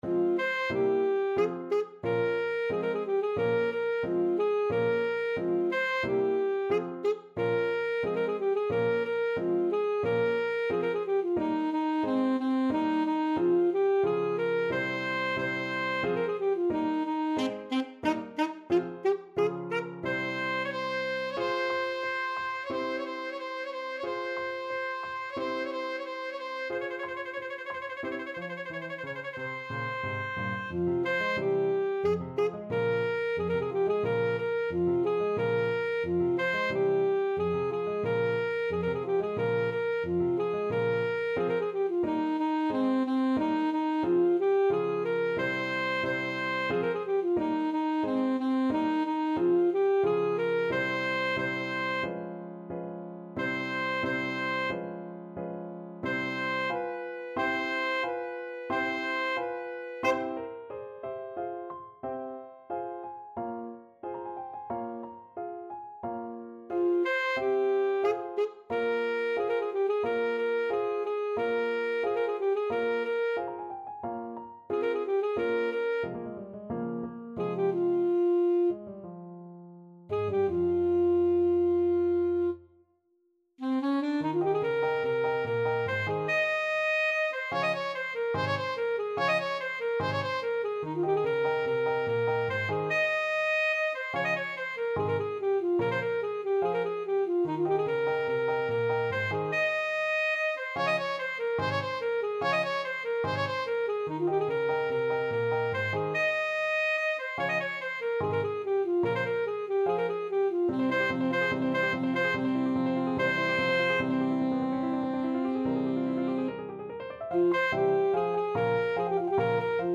Alto Saxophone
Andantino = 90 (View more music marked Andantino)
2/4 (View more 2/4 Music)
Classical (View more Classical Saxophone Music)